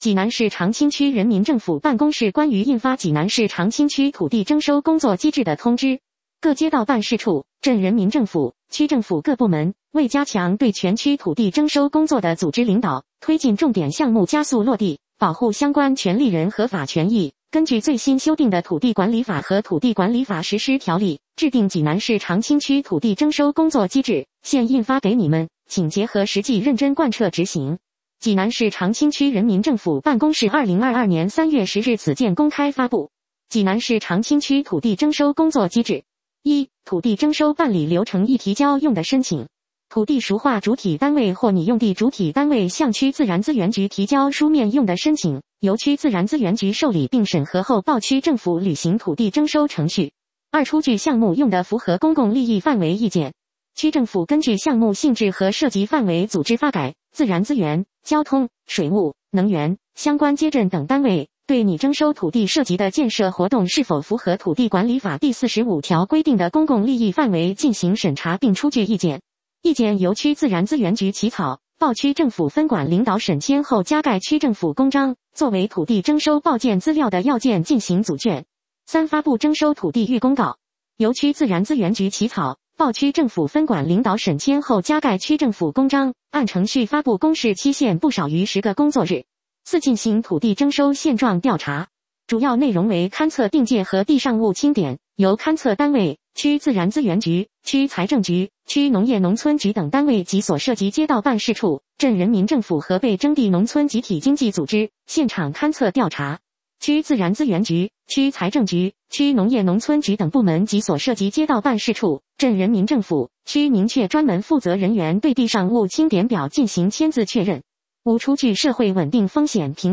济南市长清区人民政府 - 有声朗读 - 音频解读：济南市长清区人民政府办公室关于印发《济南市长清区土地征收工作机制》的通知